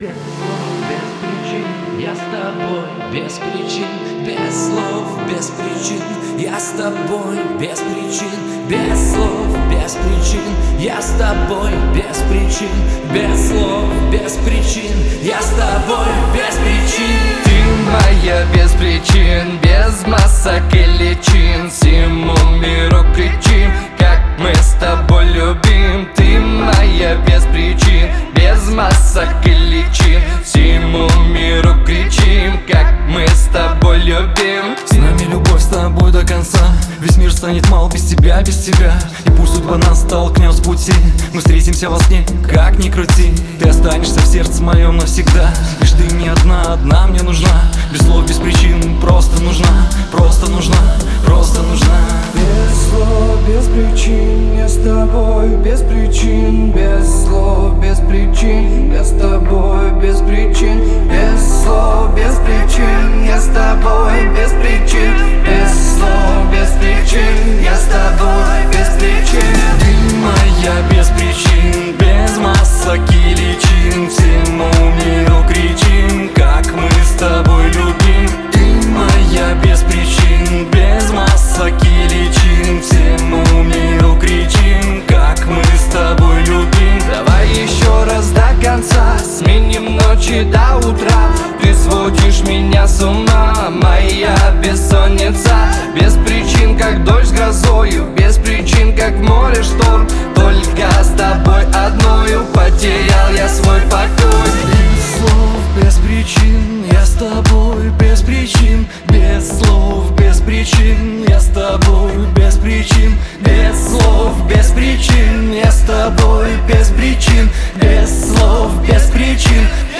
Вокал: Баритон